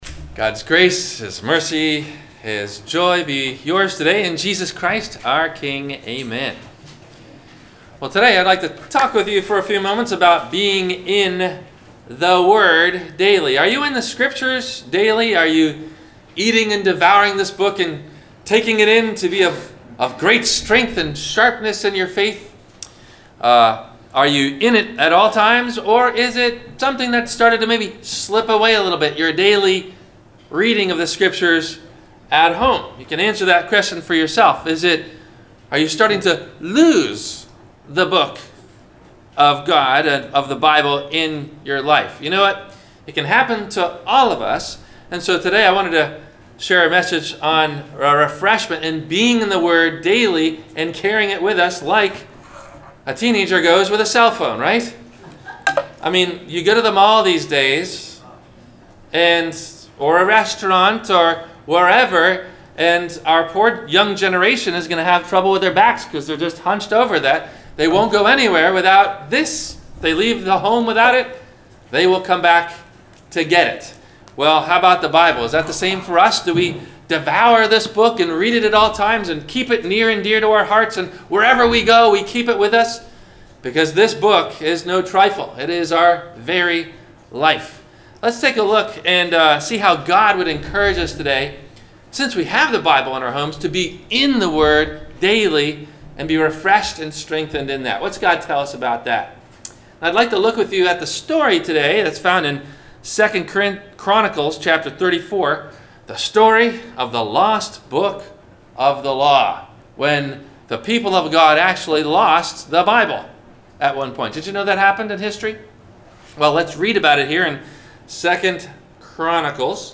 Be In The Word - Sermon - July 08 2018 - Christ Lutheran Cape Canaveral